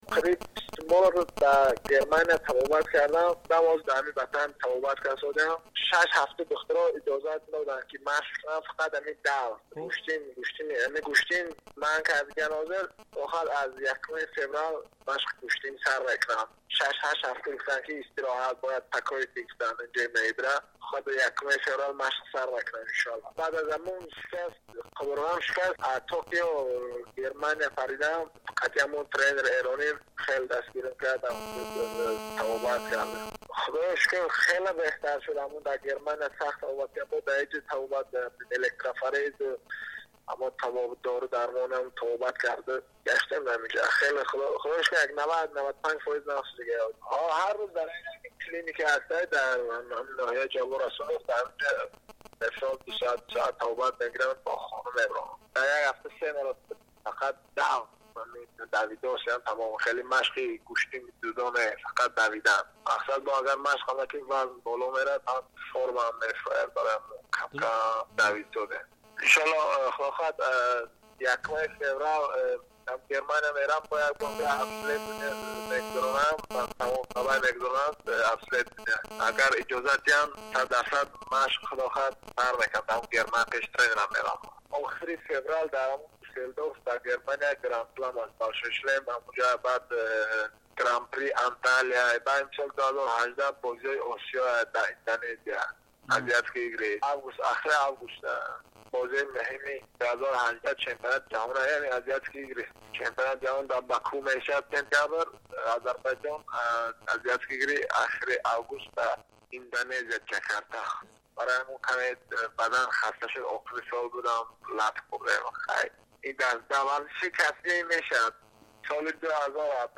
Гуфтгӯи Радиои Озодӣ бо Комроншоҳи Устопириён, паҳлавони номдори тоҷик.